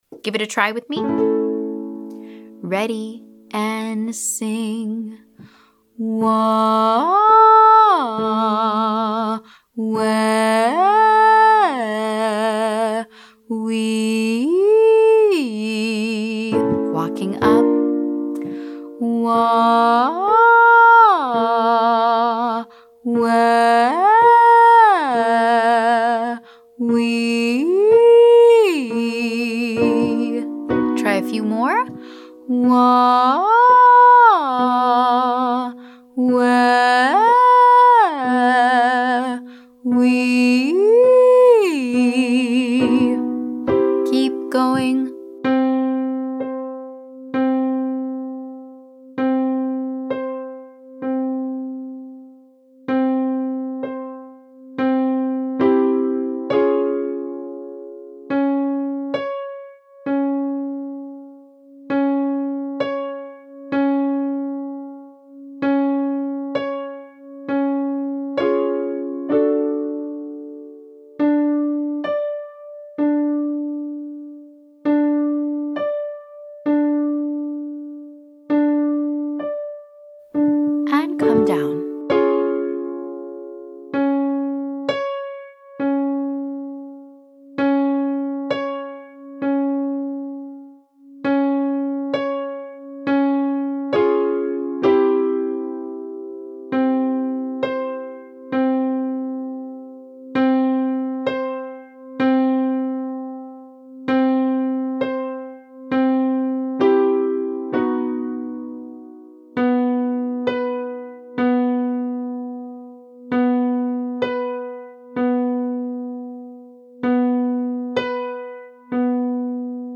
Exercise 1: Wah, Weh, Wee 1-8-1 x3 chest up
I’m using 3 different vowels, starting with a Wah, then a Weh, and lastly a Wee.